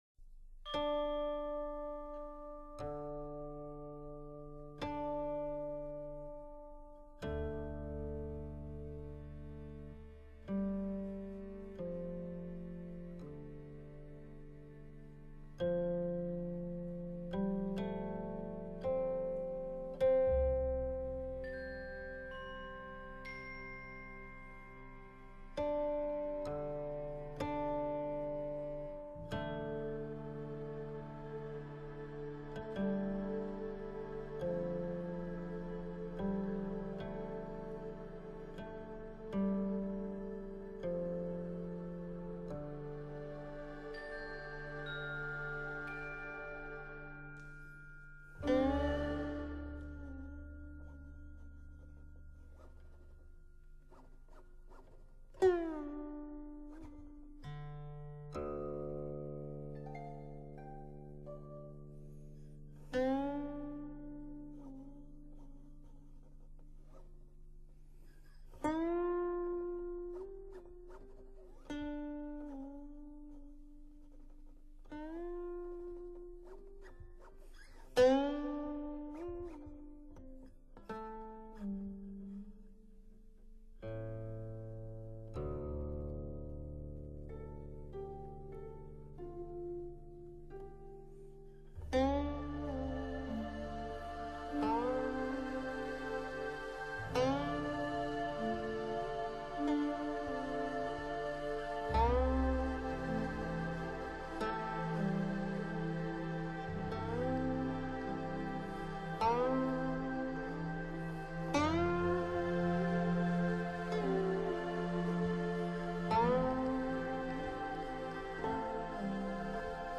传统名曲，旋律起而又伏，绵延不断，优美动听。
生动活泼的句法，悠扬婉转的音调，描写了群雁在空中盘旋、若来若去的景象。